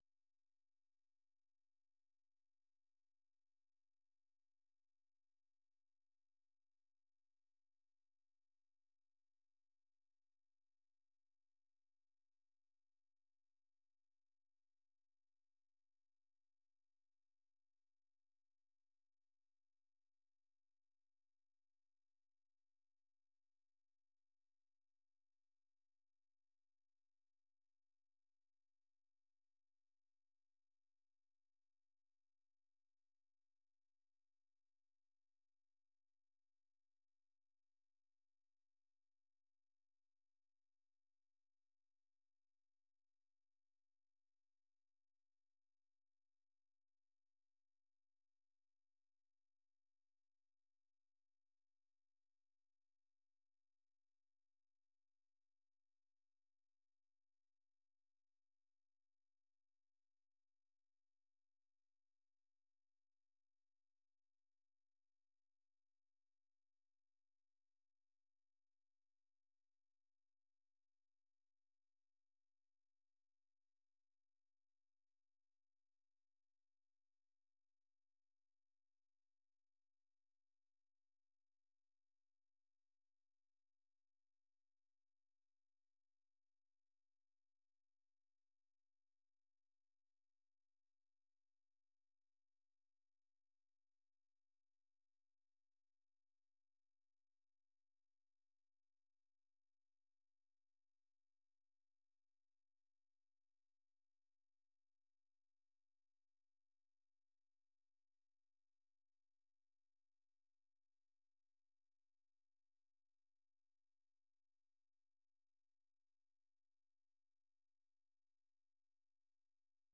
VOA 한국어 간판 뉴스 프로그램 '뉴스 투데이', 3부 방송입니다.